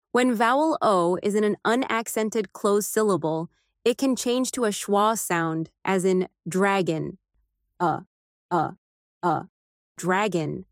When vowel O is in an unaccented, closed syllable, it can change to a schwa sound, as in “dragon”.